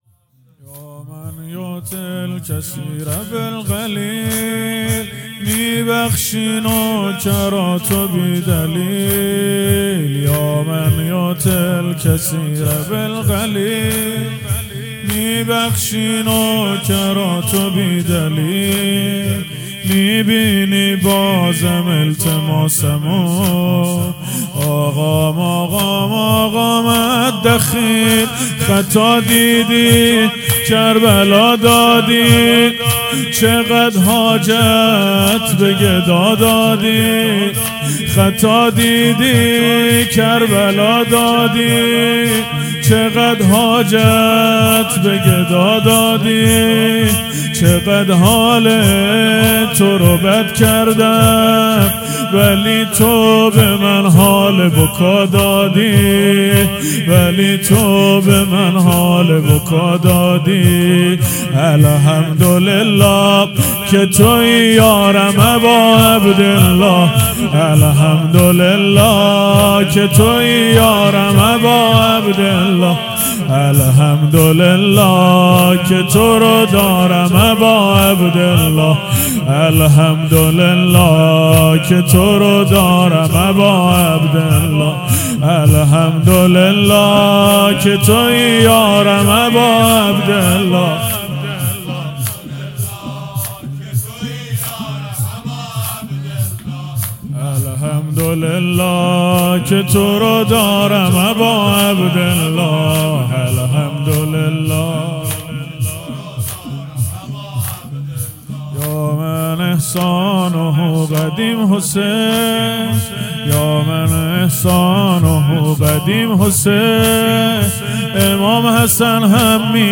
0 0 زمینه | یا من یعطی الکثیر باالقلیل
مجلس روضه هفتگی